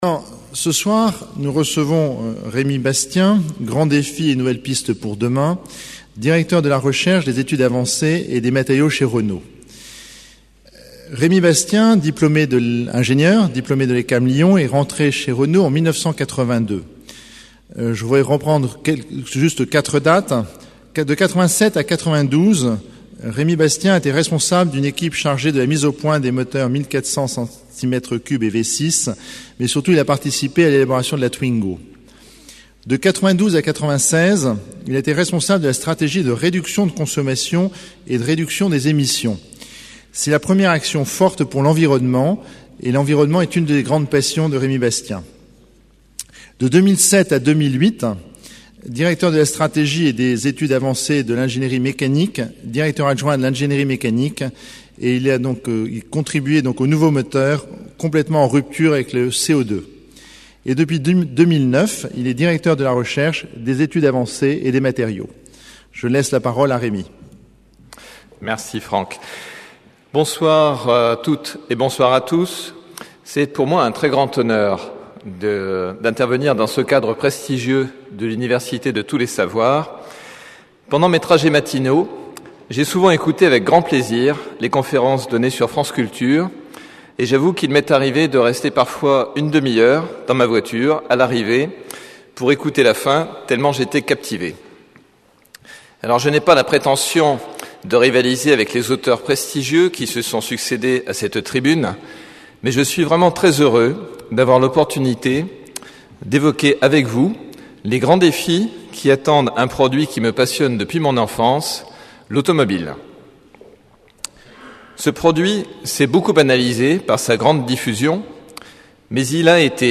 Une conférence du cycle : Qu'est-ce qu'un ingénieur aujourd'hui ?